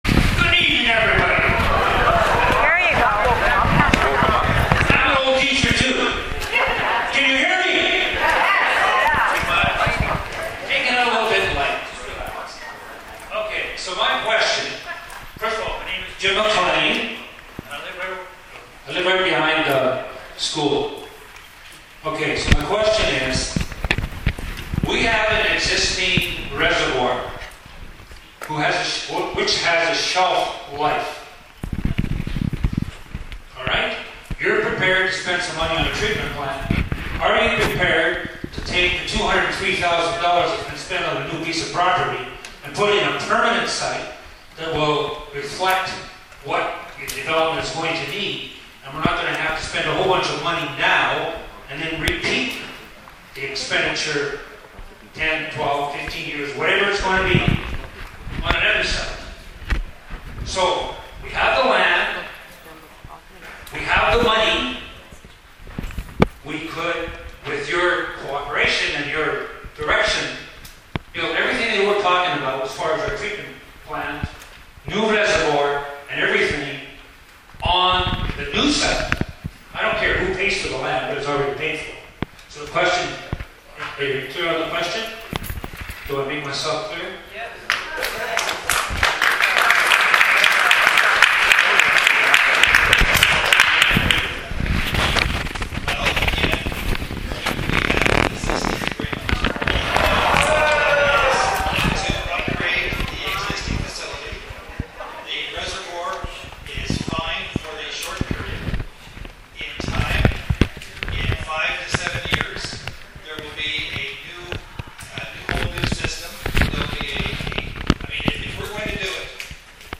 Another clip of landowner asking why we can’t pool our money and put in a permanent treatment facility instead of spending money now only to have to do it again in a few years.